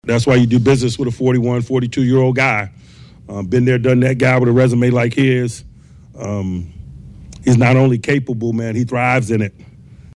Tomlin was grateful for the leadership of his veteran quarterback.
nws0582-mike-t-rodgers-rules.mp3